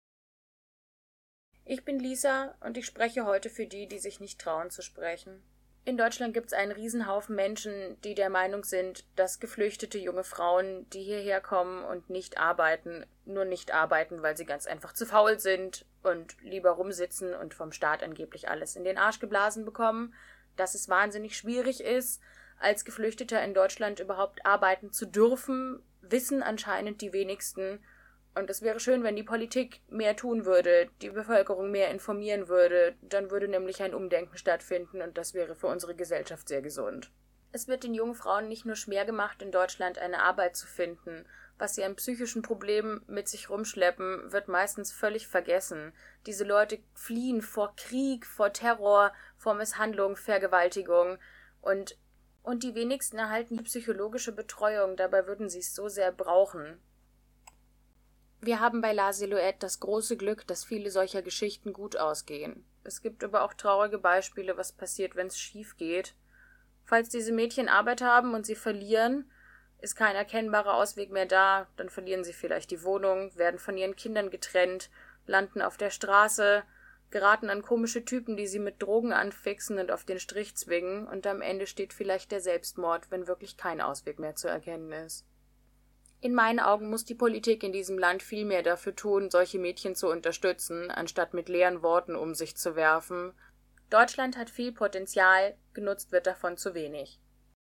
Junge Frauen aus München, mit und ohne Zuwanderungsgeschichte, beschrieben in sehr persönlichen Worten die Bedeutung von Bildung und Berufsausbildung für ihre Biografie.
Produziert wurden die O-Töne speziell für das Fachforum.